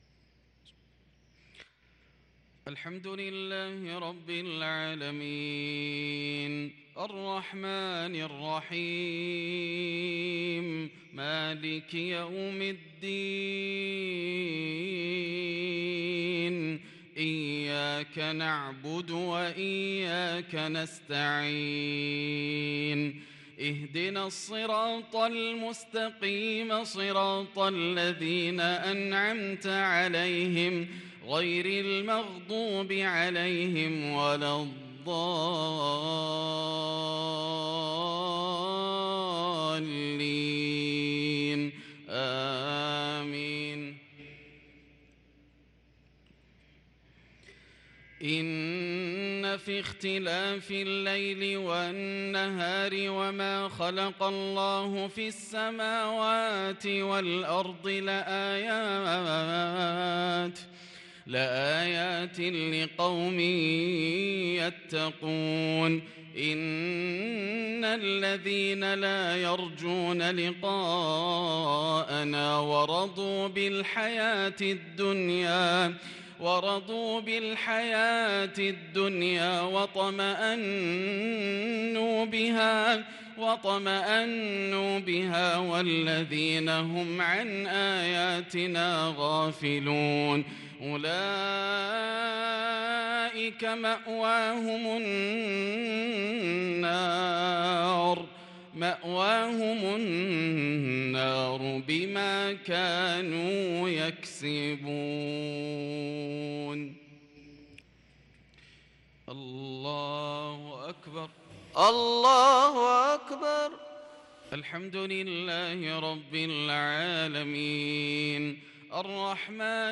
صلاة المغرب للقارئ ياسر الدوسري 4 صفر 1444 هـ
تِلَاوَات الْحَرَمَيْن .